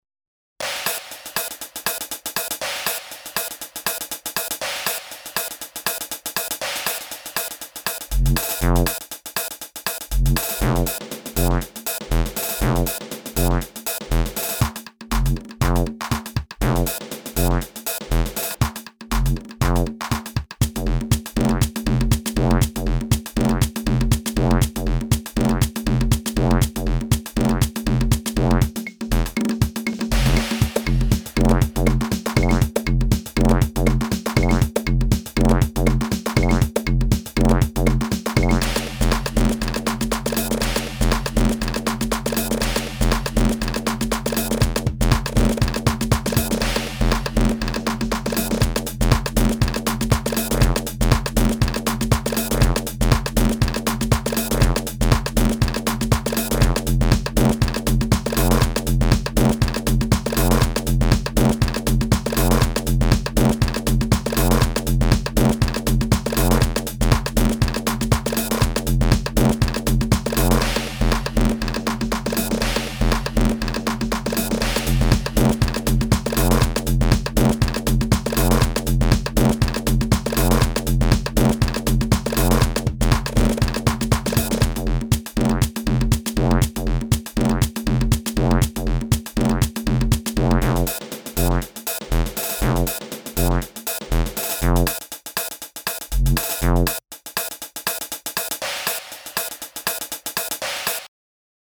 One of the first fully-digital affordable drum machine based on compresed PCM acoustic samples.
Hear a synched sequence
tr626-mix.mp3